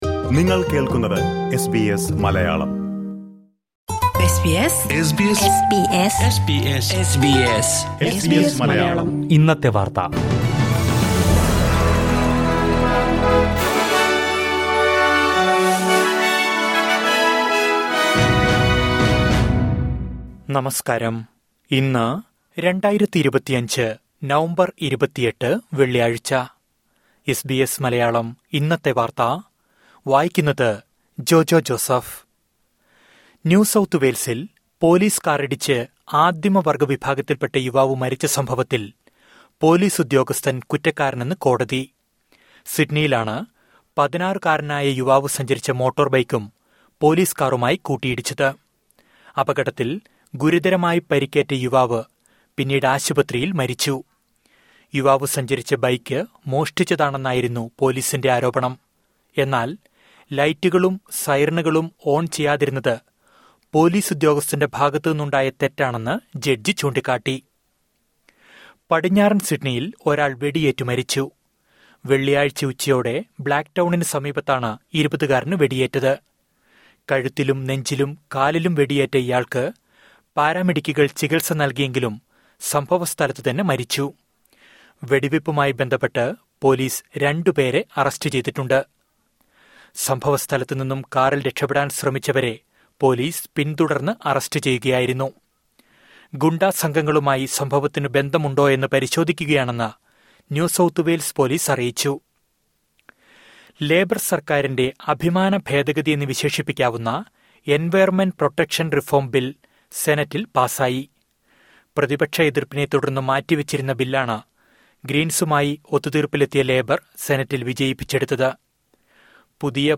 2025 നവംബർ 28ലെ ഓസ്ട്രേലിയയിലെ ഏറ്റവും പ്രധാന വാർത്തകൾ കേൾക്കാം...